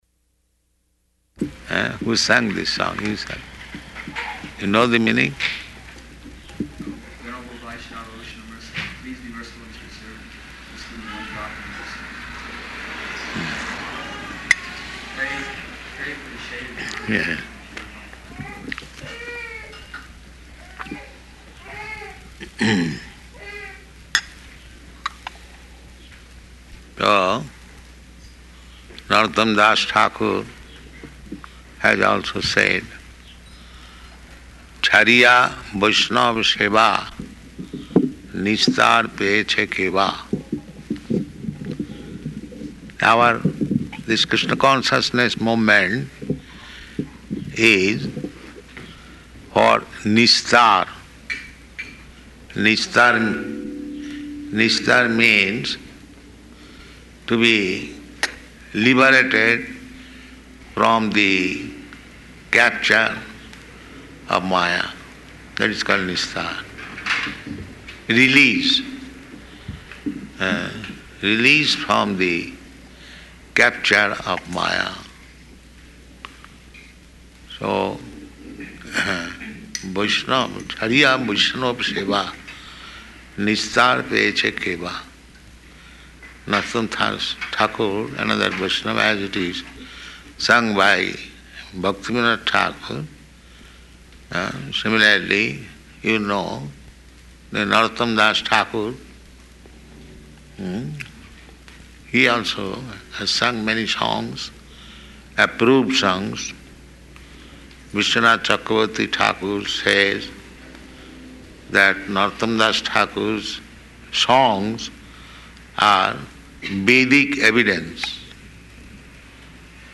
Arrival Address
Location: Denver